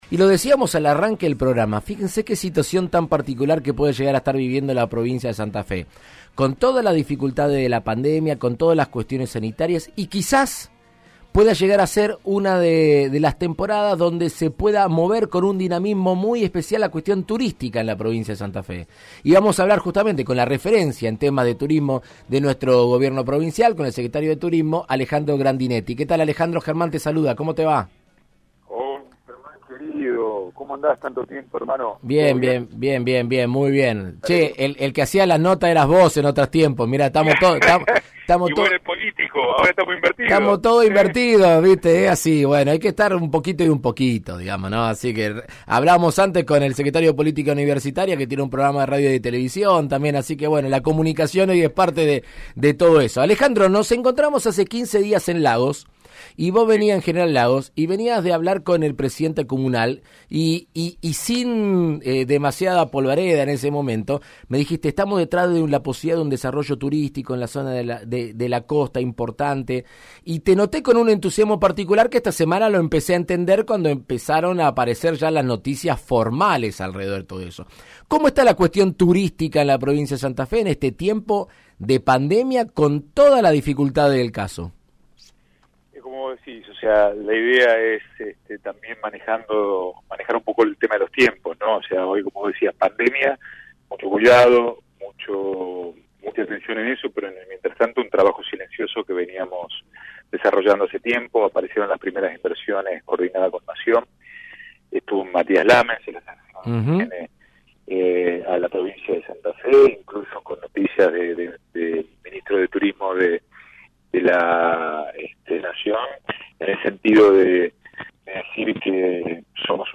En Argentina Unida contra el Coronavirus, el secretario de turismo de la provincia, Alejandro Grandinetti, dialogó con el diputado nacional Germán Martínez sobre la apuesta por el turismo que realiza el gobierno de Omar Perotti y habló acerca de los programas Pre-viaje y Santa Fe Plus.